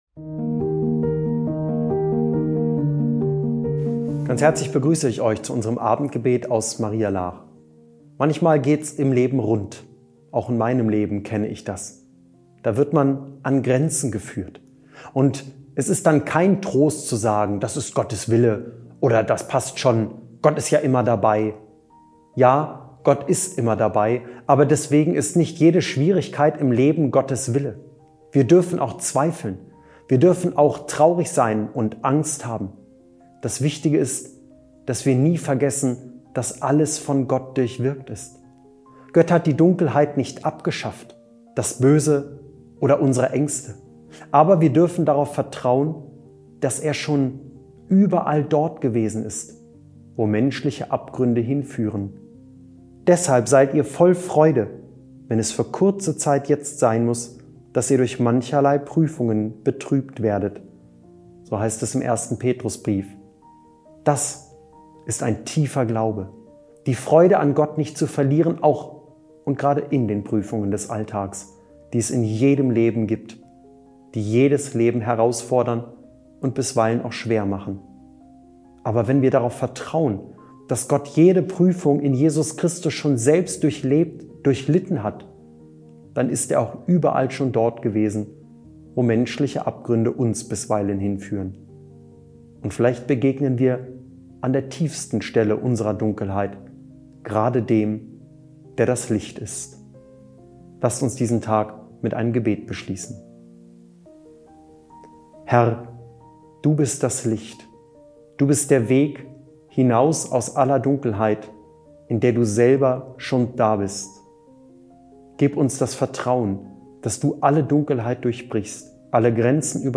Abendgebet